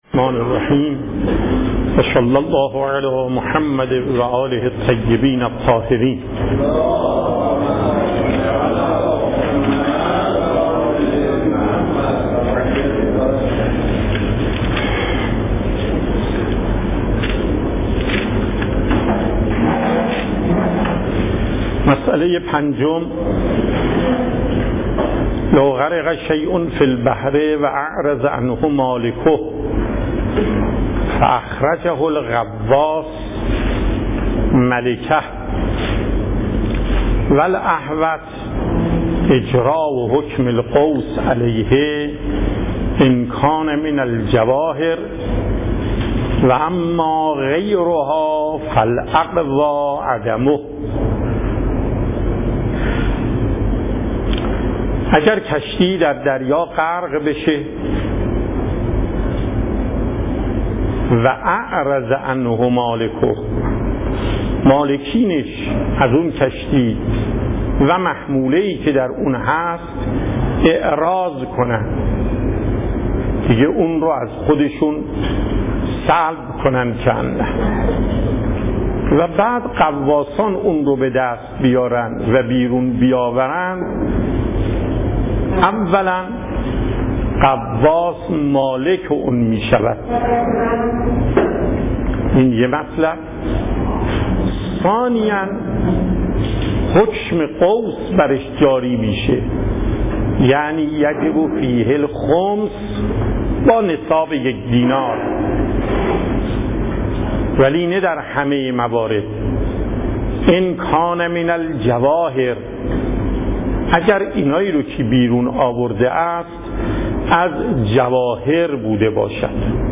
صوت و تقریر درس پخش صوت درس: متن تقریر درس: ↓↓↓ تقریری ثبت نشده است.